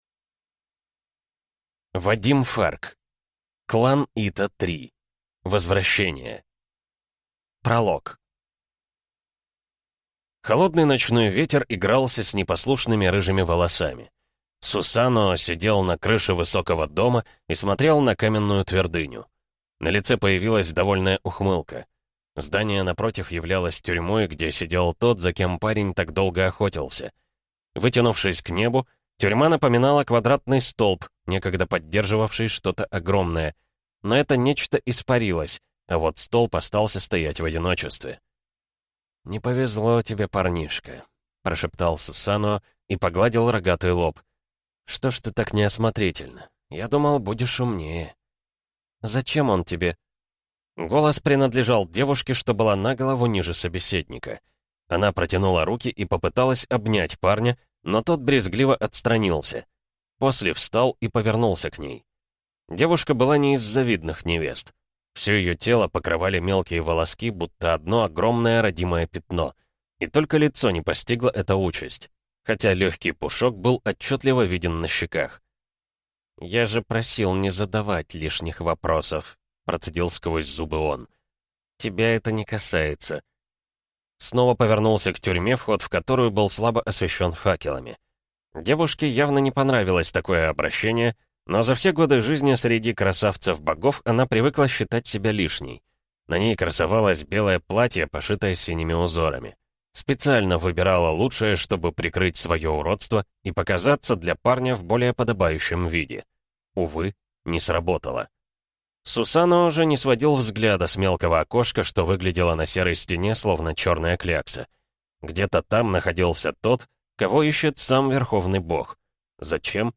Аудиокнига Клан Ито. Возвращение | Библиотека аудиокниг